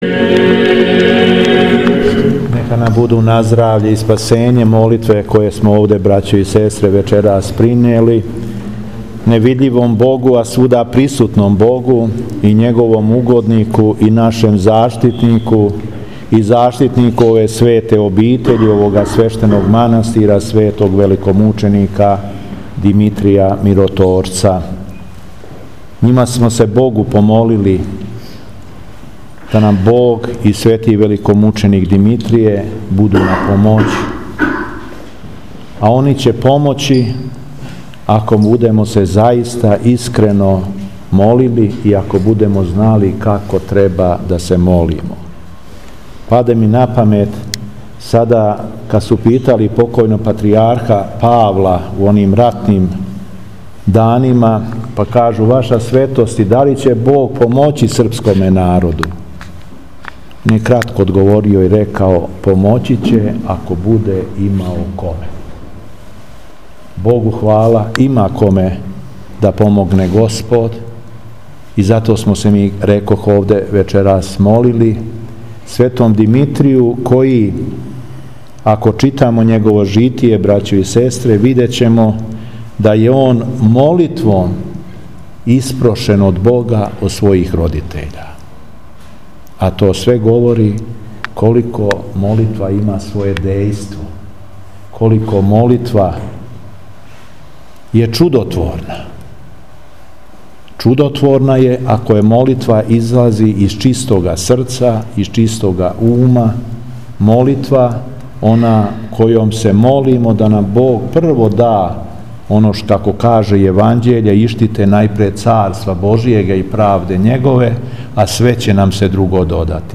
Истог дана, Његово Преосвештенство Епископ шумадијски Господин Јован, служио је празнично бденије у манастиру Светог великомученика Димитрија у Бајчетини. Поучавајући окупљене вернике Епископ је нагласио, осврнувши се на житије Светог Димитрија, да је молитва чудотворна. Чудотворна је само уколико излази из чистог срца и чистог ума.
Беседа Епископа шумадијског Г. Јована у Бајчетини